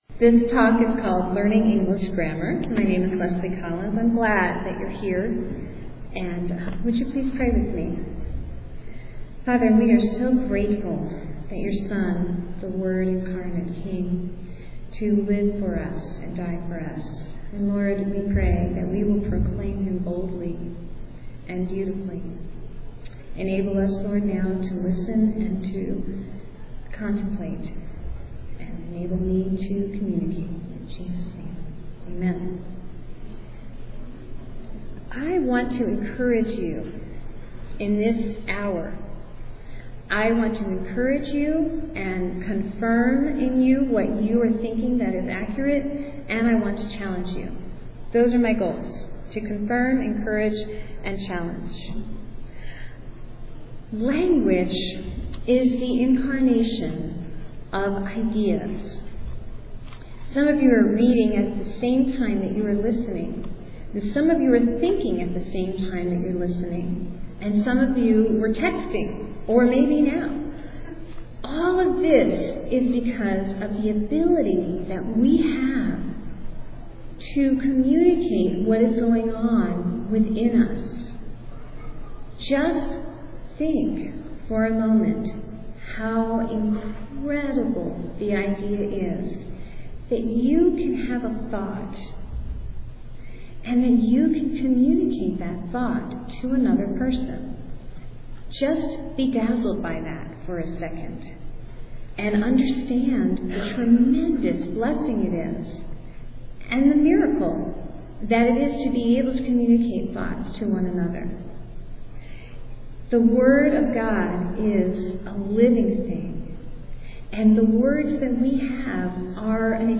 2007 Workshop Talk | 0:59:31 | K-6, Rhetoric & Composition